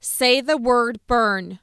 HAS-Corpus / Audio_Dataset /anger_emotion /1734_ANG.wav